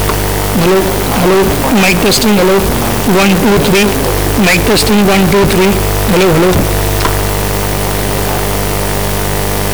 我们可以使用以下 amixer 命令进行录制、但 在播放该音频(.wav)文件时只能听到噪声。
听到的是、我们正在通过 RCA 插孔使用 MIC。
目前、当我们听到捕获的音频时、我们听到了很多噪音。
record_5F00_humanVoice.wav